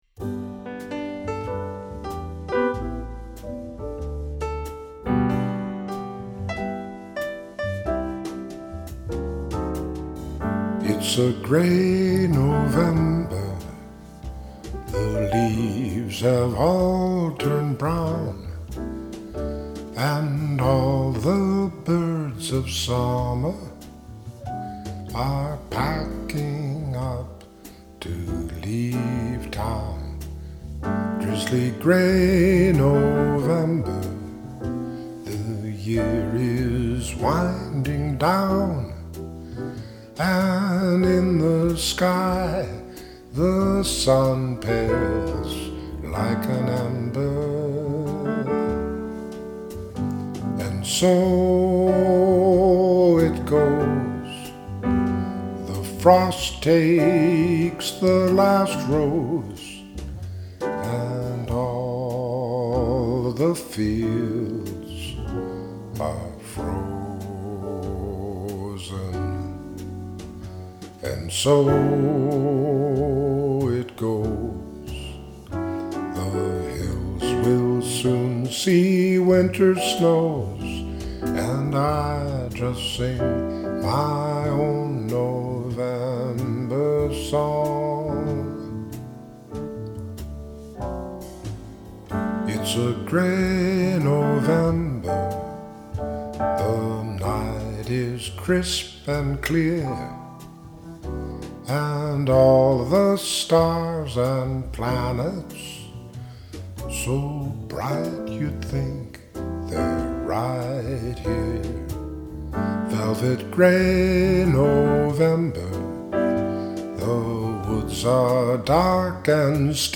soprano
piano in concert at St. Anselm College